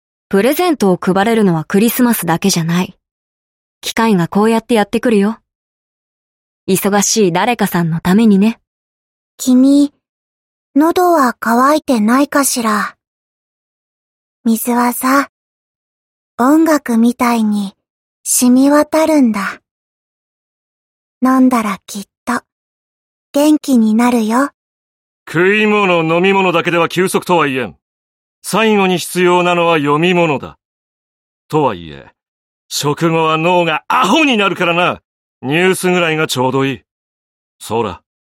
声优 花守由美里&井口裕香&子安武人